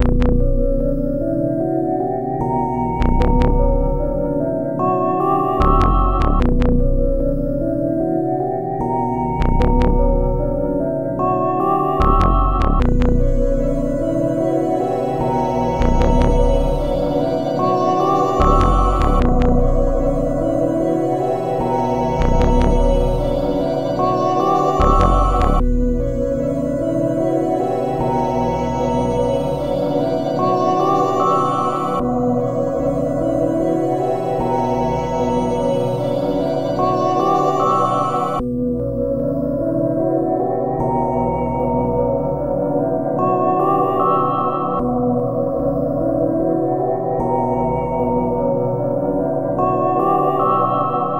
rip screw [150bpm] C.wav